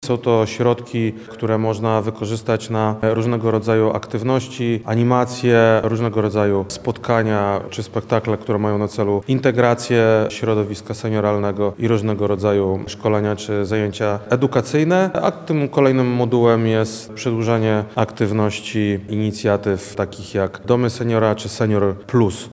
– Dofinansowanie pozyskane w ramach konkursu może zostać przeznaczone na kilka form działalności – mówi wojewoda lubelski Krzysztof Komorski.